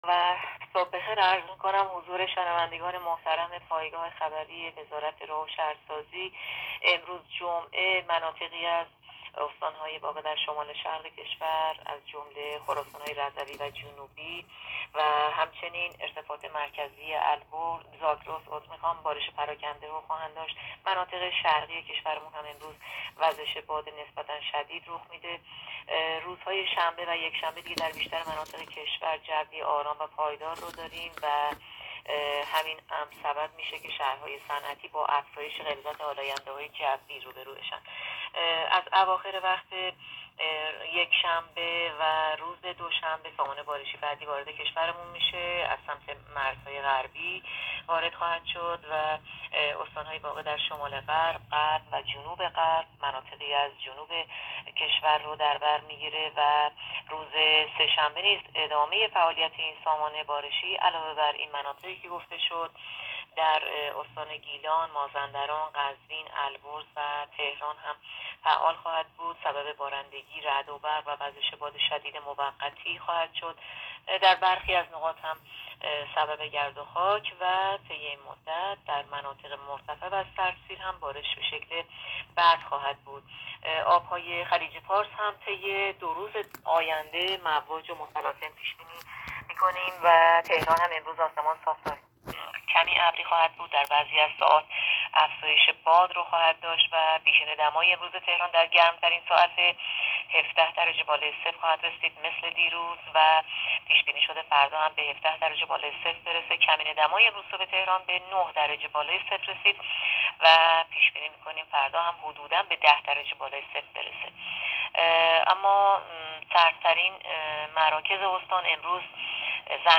گزارش رادیو اینترنتی پایگاه‌ خبری از آخرین وضعیت آب‌وهوای دوم آذر؛